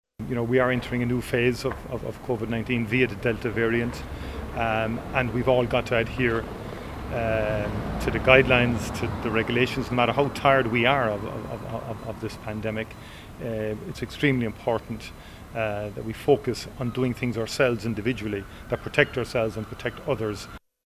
Micheál Martin says he knows people are fed up but we need to be vigilant.